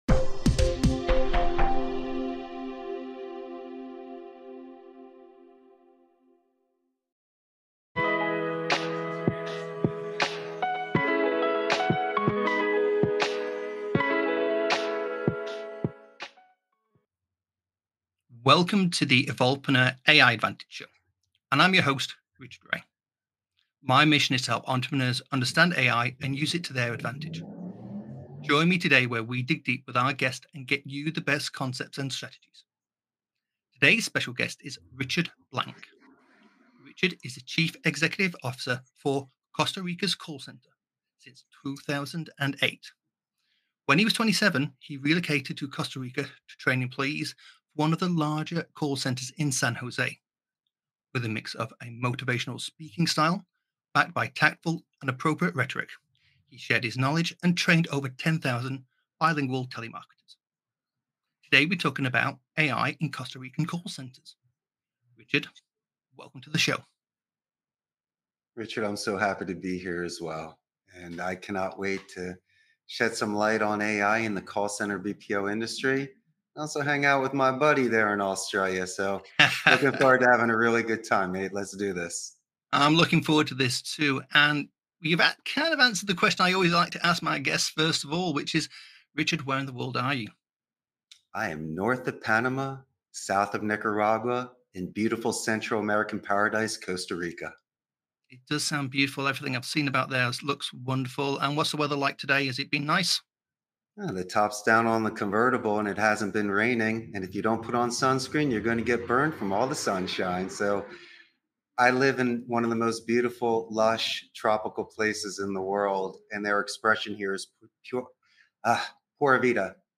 with guest